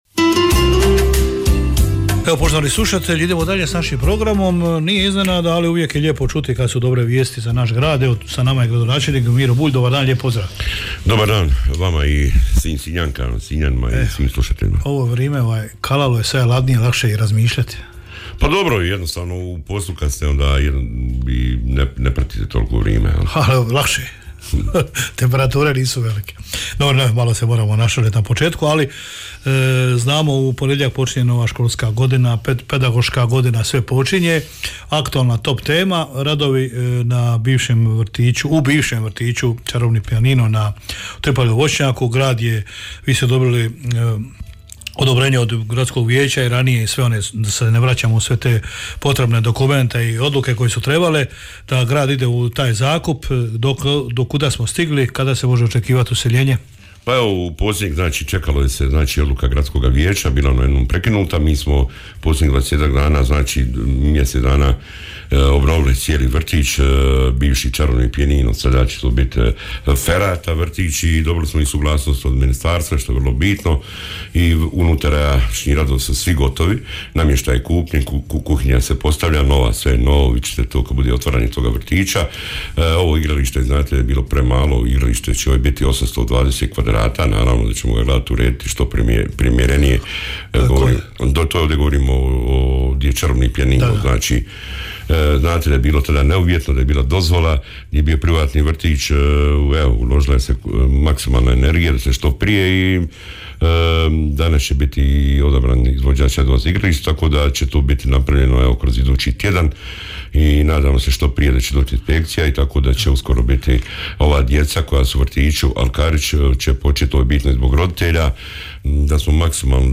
U programu Hit radija danas je gostovao gradonačelnik Grada Sinja Miro Bulj.